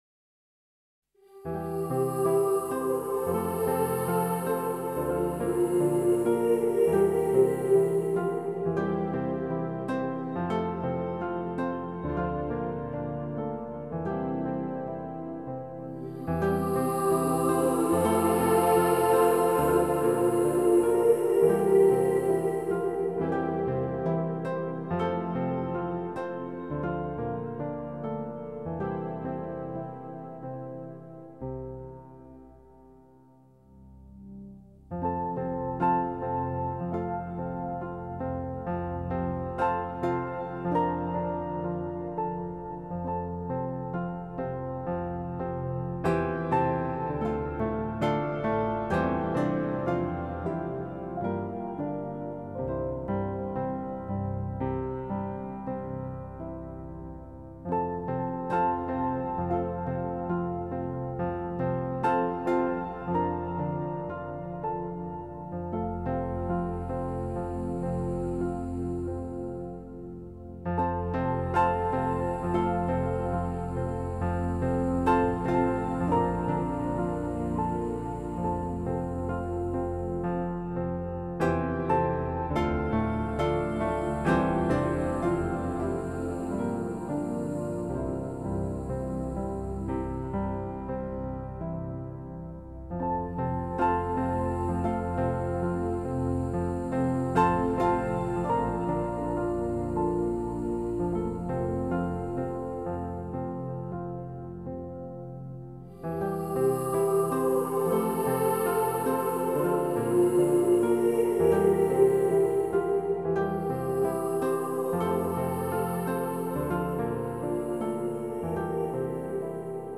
장르: Electronic, Folk, World, & Country
스타일: New Age, Modern Classical, Ambient